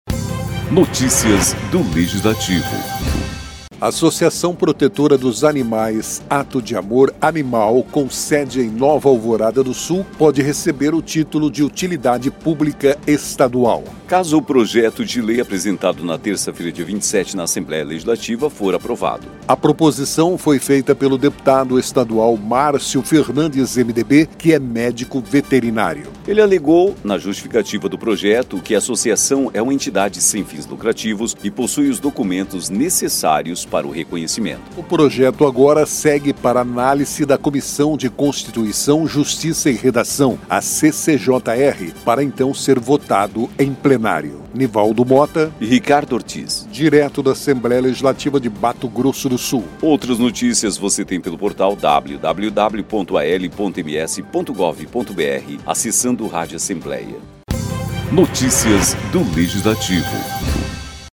Locução: